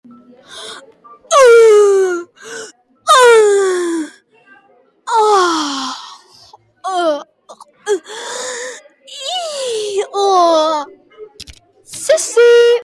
Play Gemidos Intenso De Un Templario - SoundBoardGuy
gemidos-intenso-de-un-templario.mp3